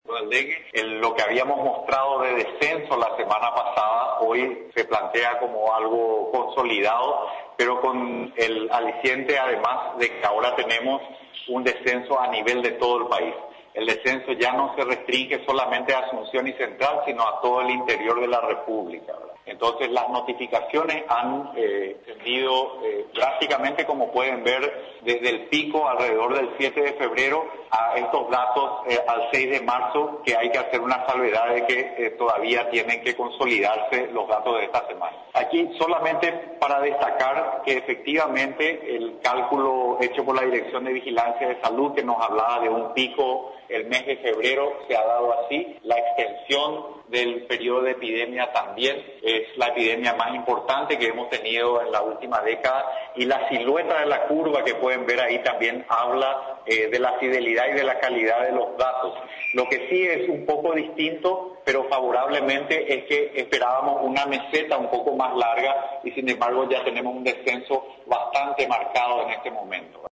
El ministro de Salud Pública y Bienestar Social (MSPYBS), Julio Mazzoleni, confirmó este viernes, en rueda de prensa, que se produjo una disminución de los casos de dengue a nivel país.